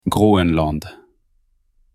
フランス語ではどう言うかご存じですか？
このように言います。
Groenland.mp3